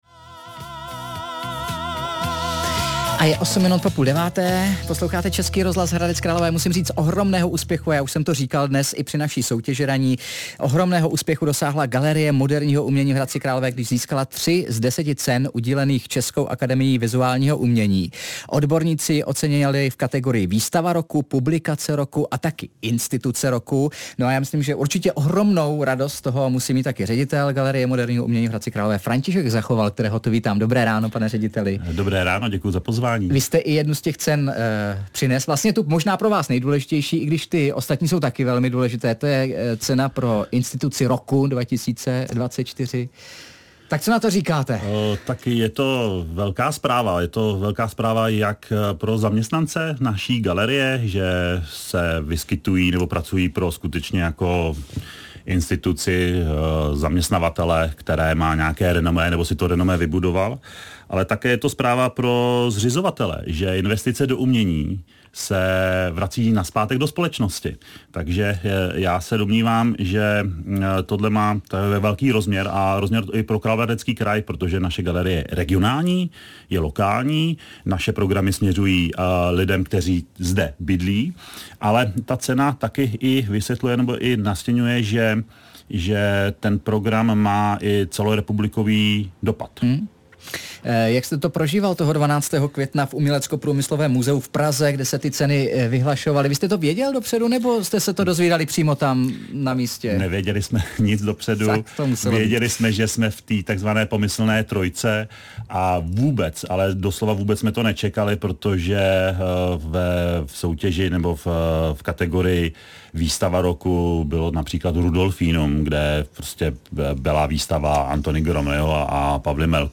Host ve studiu: Výstava roku, Publikace roku a Instituce roku. Ocenění pro Galerii moderního umění v Hradci Králové - 12.06.2025